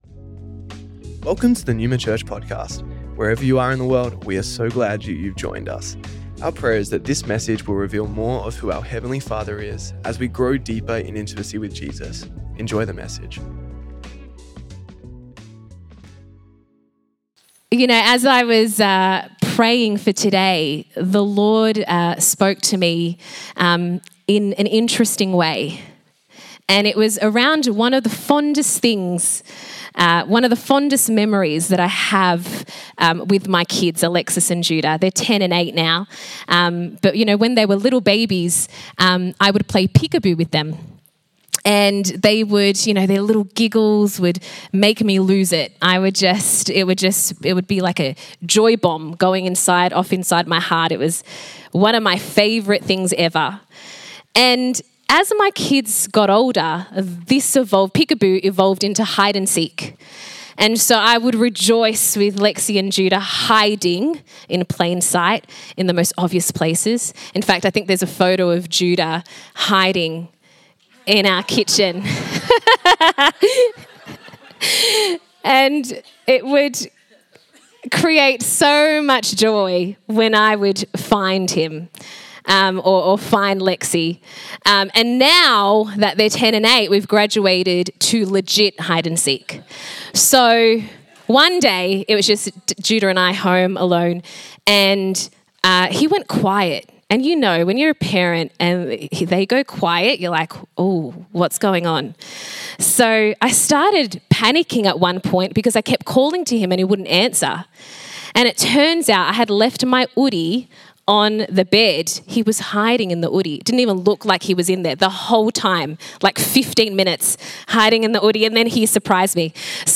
Recorded at Melbourne Neuma West